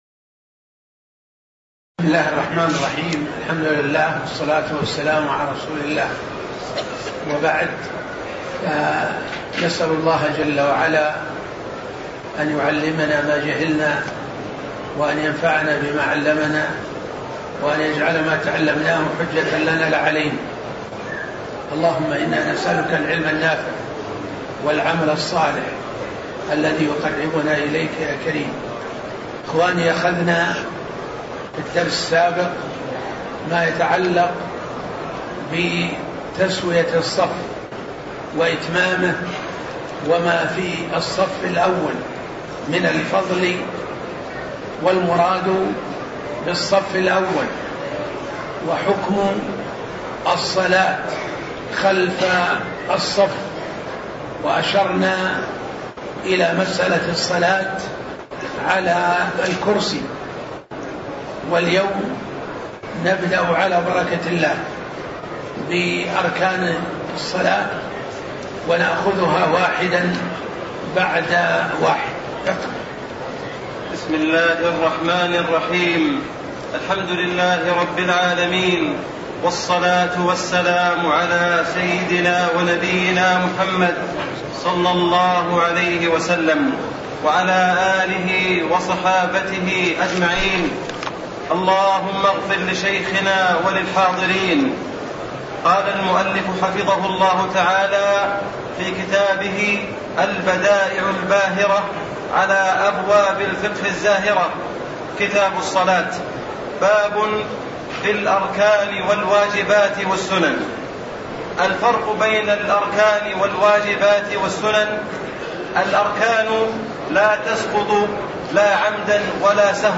تاريخ النشر ٥ رجب ١٤٣٨ هـ المكان: المسجد النبوي الشيخ